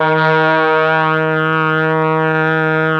RED.BRASS  9.wav